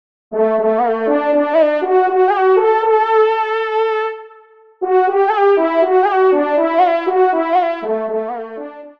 FANFARE
Dédicace : Fanfare de l’Équipage